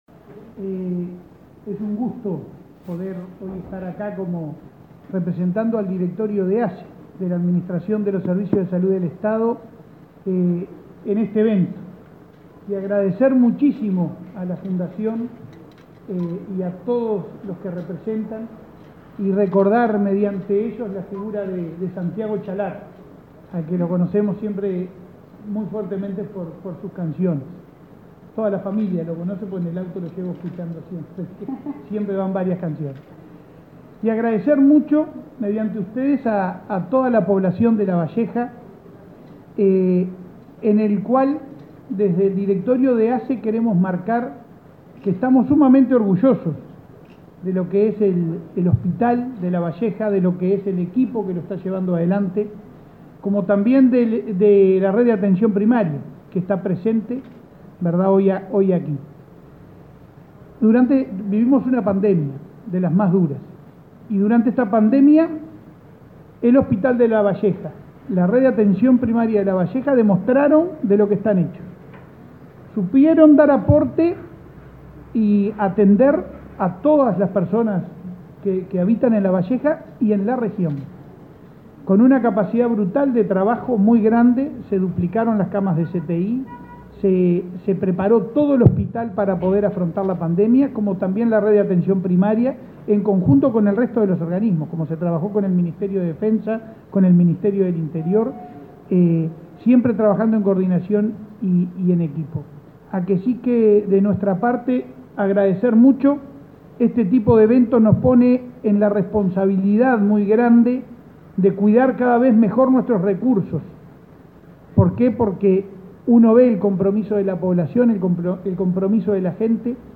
El presidente de ASSE, Leonardo Cipriani; el intendente de Lavalleja, Mario García, y el ministro de Turismo, Tabaré Viera, participaron este jueves